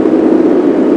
ringing.mp3